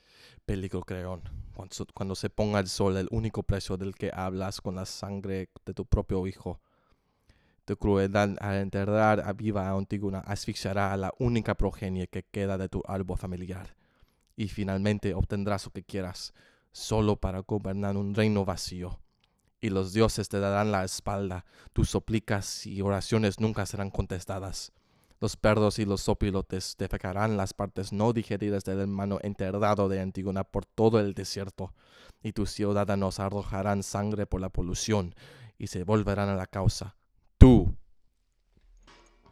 On the last sentence I added a vocal reverb using QLab that shook the theater and left us experiencing the daunting feeling that Ixiim felt throughout the paly.